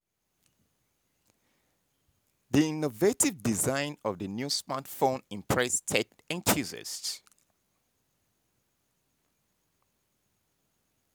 surprised.wav